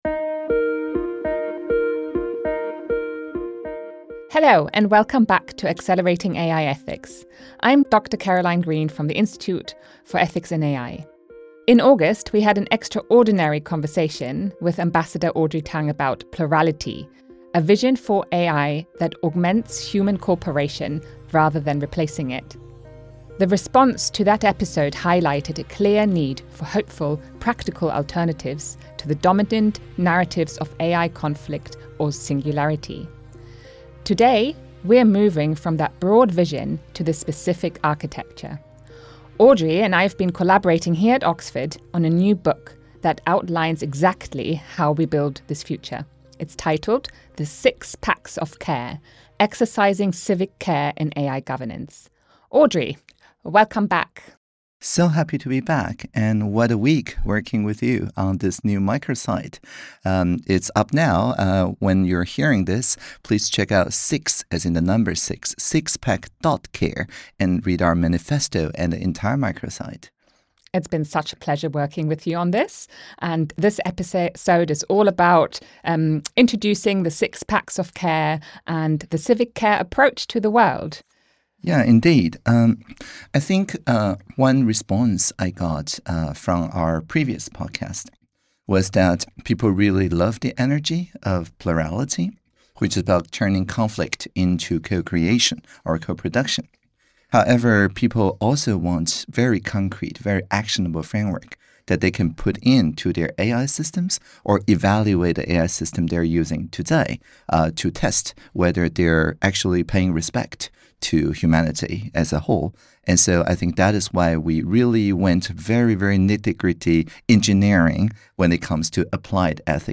From real-world applications in social care to global policy discussions, this conversation offers hopeful, actionable pathways for creating technology that supports pluralism, community, and relational health.